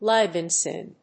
アクセントlíve in sín